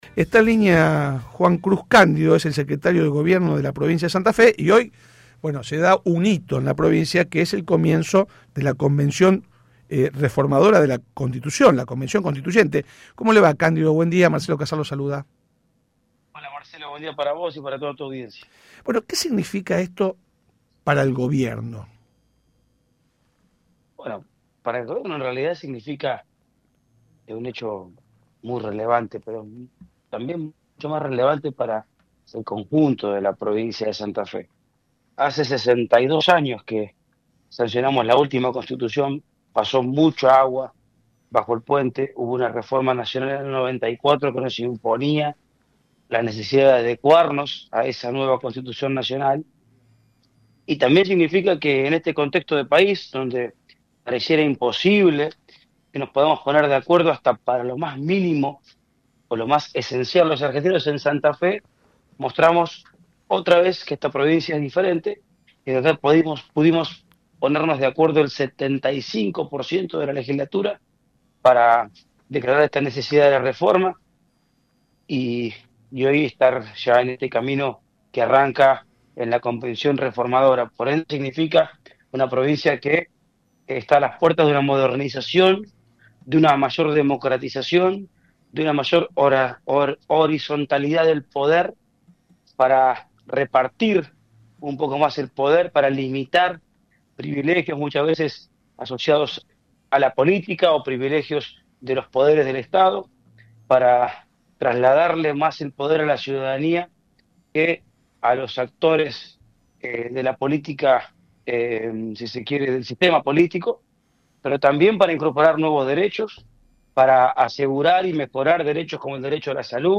El secretario de Gobierno de la provincia de Santa Fe, Juan Cruz Cándido, habló en el programa La Barra de Casal en el marco del comienzo de la Convención Constituyente que reformará la carta magna santafesina y dijo que es una gran oportunidad para rubricar nuevos valores y derechos para los santafesinos.